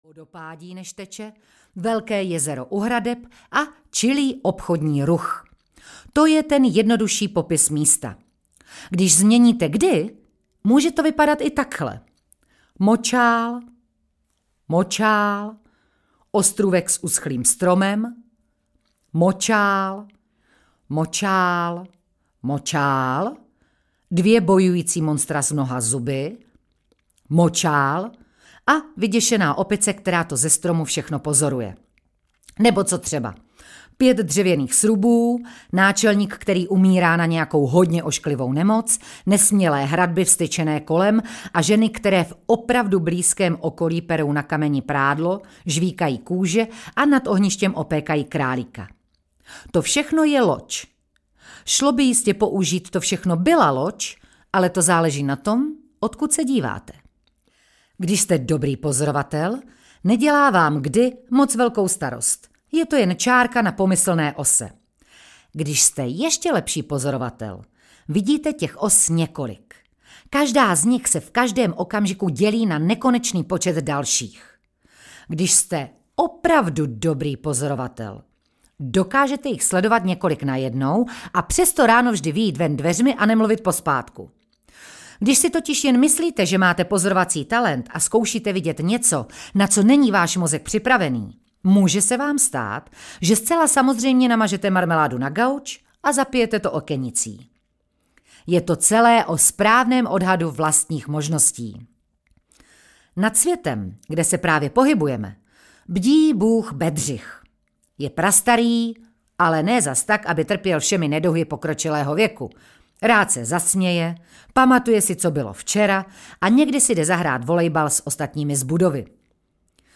Mořičvíl audiokniha
Ukázka z knihy
moricvil-audiokniha